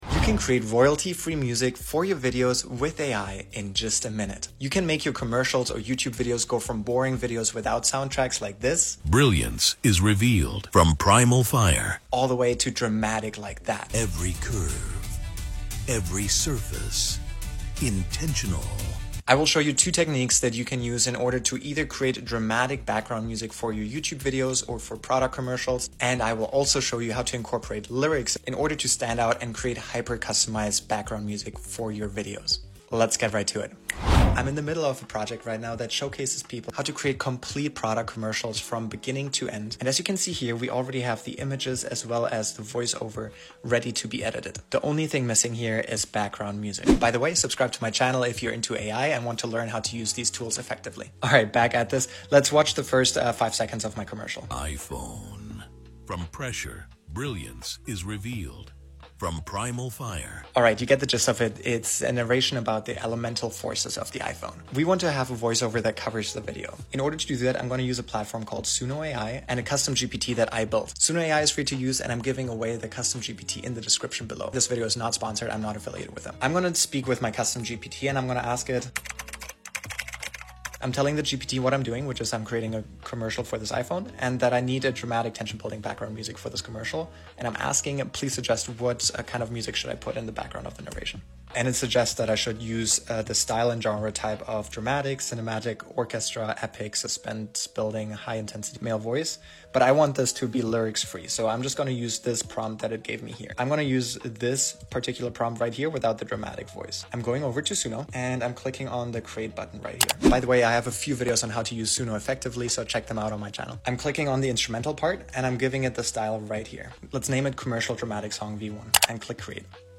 In this tutorial, I demonstrate two powerful techniques for creating professional-quality background music using Suno AI. First, learn how to generate dramatic, cinematic instrumentals that perfectly complement your video content. Then, discover how to create customized jingles with clever lyrics that make your videos stand out.